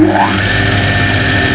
Engine5
ENGINE5.WAV